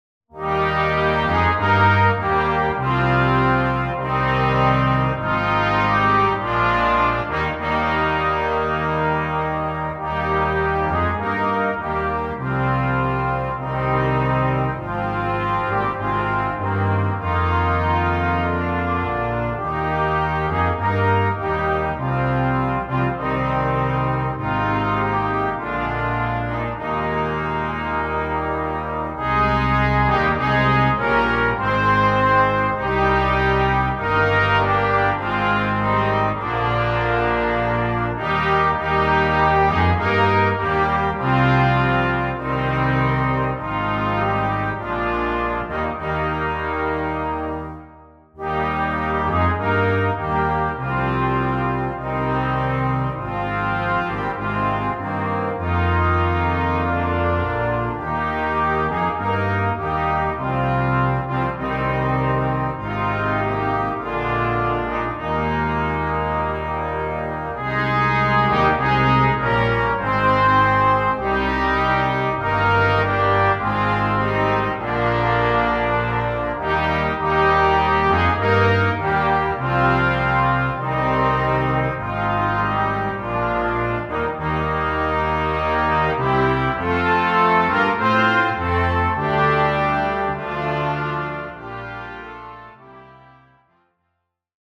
Gattung: Weihnachtsmusik für Brass Quintett
Besetzung: Instrumentalnoten > Weihnachten > 5 Instrumente